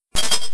effect_ice1.wav